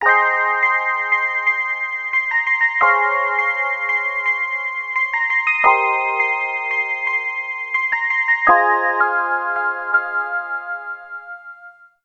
Roland Juno-6 Polyphonic Analog Synthesizer | smem
shiny lead 00:12
smem_juno-6_shiny_lead_0.mp3